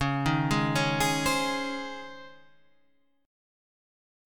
C#M7sus2 chord